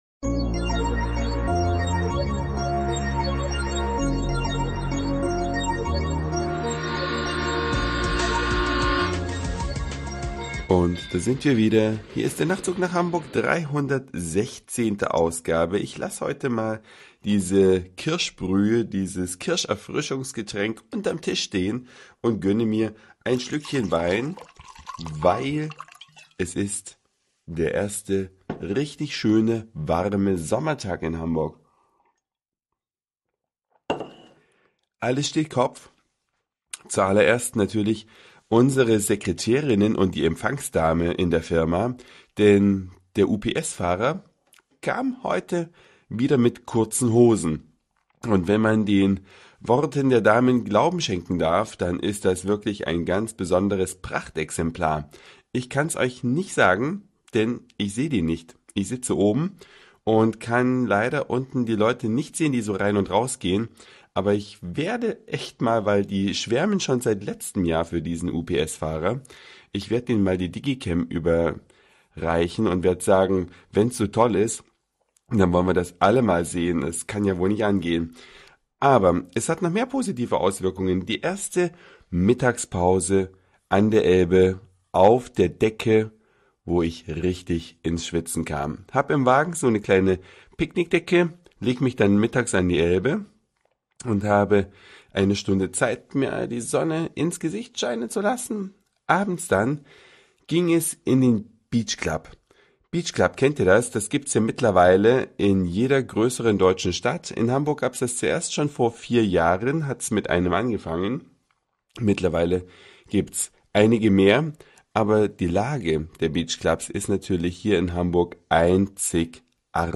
Stimme heute etwas anders.